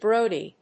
/ˈbrodi(米国英語), ˈbrəʊdi:(英国英語)/